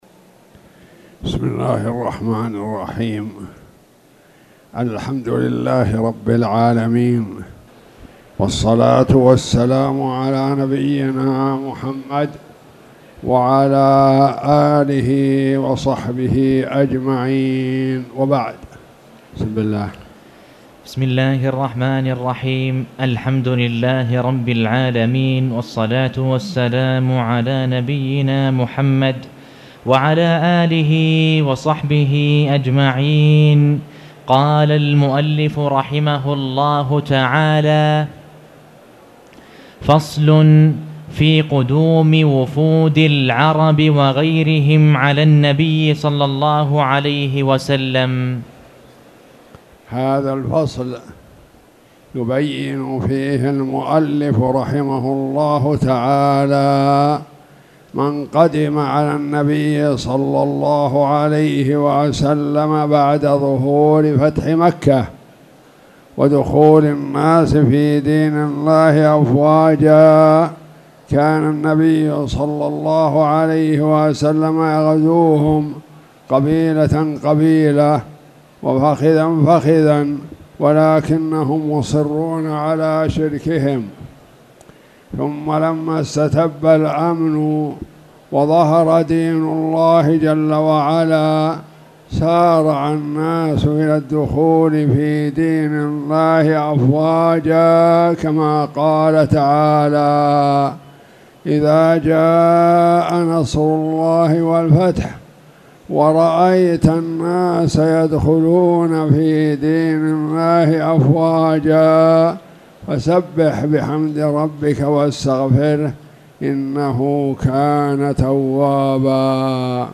تاريخ النشر ١٣ جمادى الأولى ١٤٣٨ هـ المكان: المسجد الحرام الشيخ